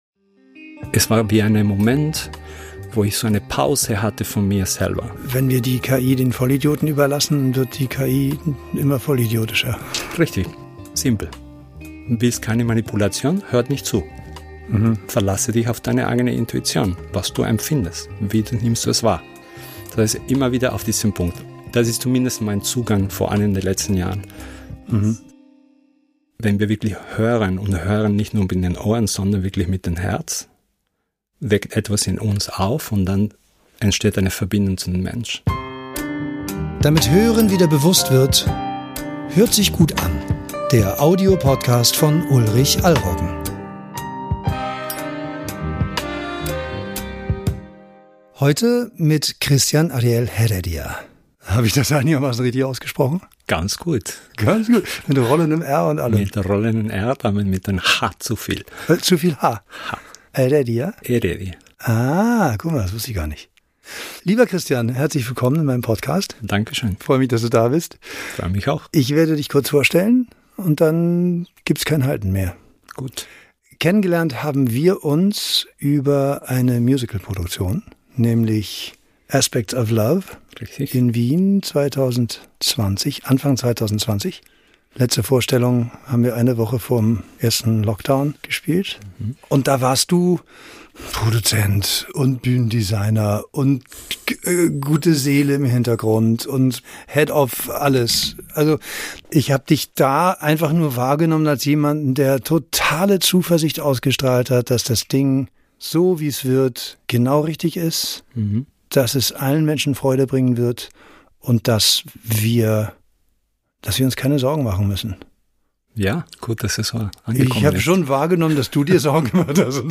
Ein Gespräch wie ein guter argentinischer Rotwein: mit Wärme und Tiefgang Mehr